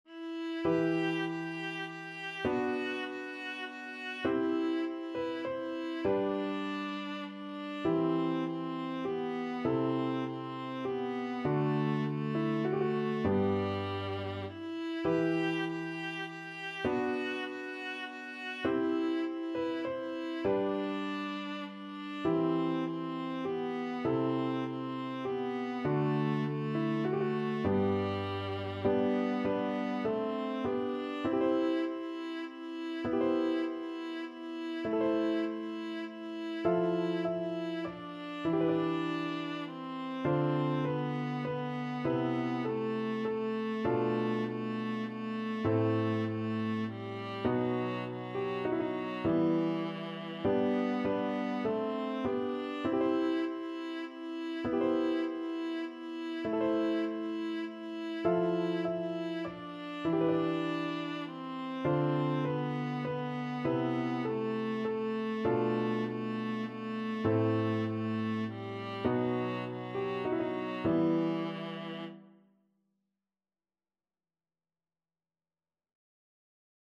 Viola
E minor (Sounding Pitch) (View more E minor Music for Viola )
E4-G5
Classical (View more Classical Viola Music)
monteverdi_si_dolce_el_tormento_VLA.mp3